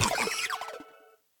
Cri de Fragroin femelle dans Pokémon Écarlate et Violet.